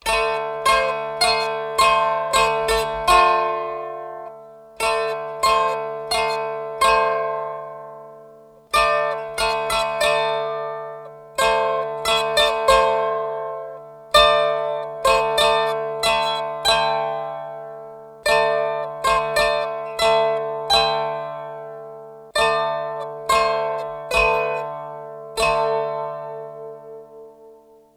Außerdem hab ich wieder auf meinem Dulcimer rumgeklimpert und MP3's davon gemacht.